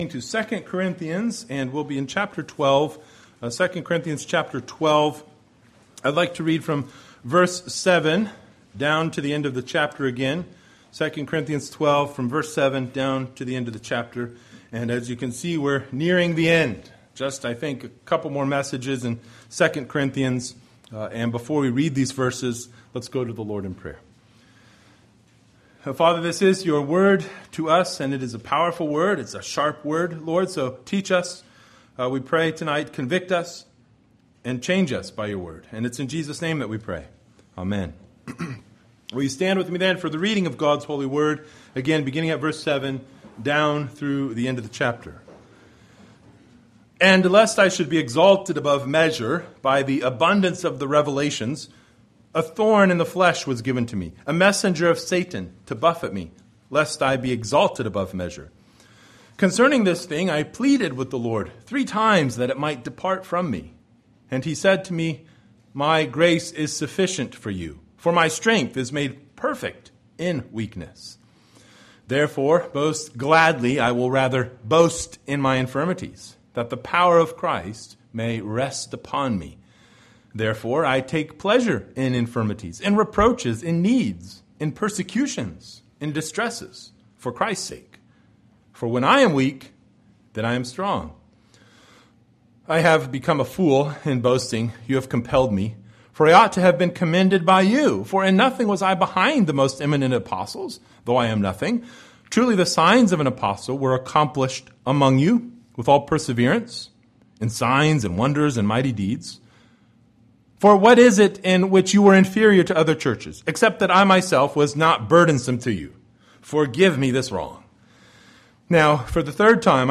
Passage: II Corinthians 12:7-21 Service Type: Sunday Evening